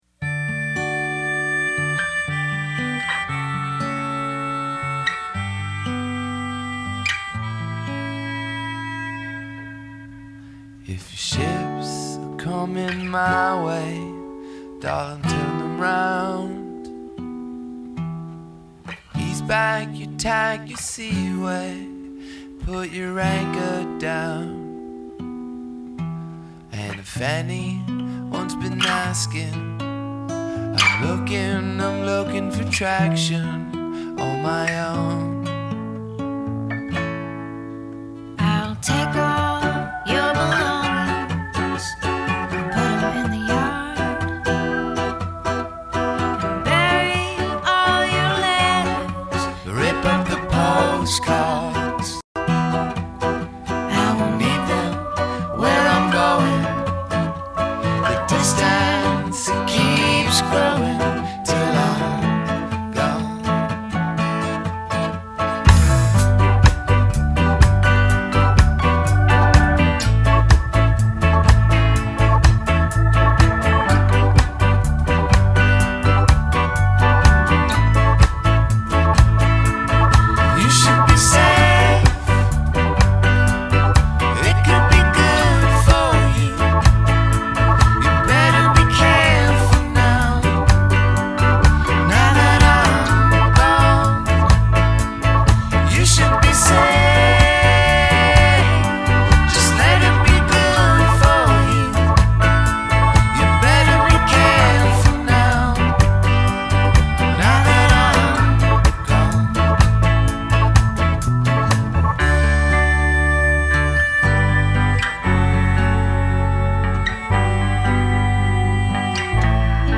duet